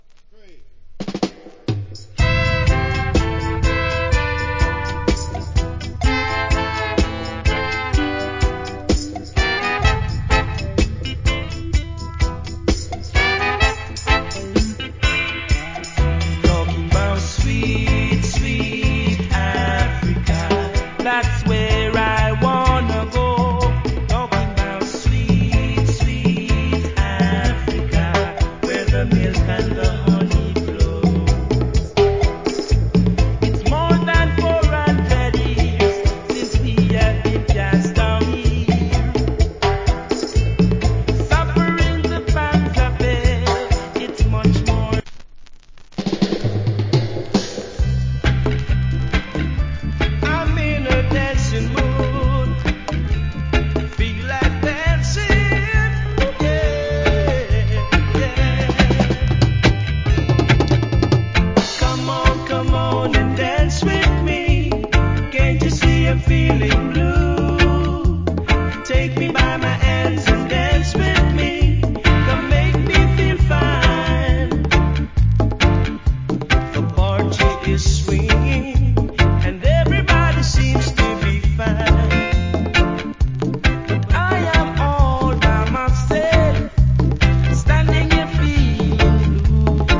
Killer Roots Rock Vocal.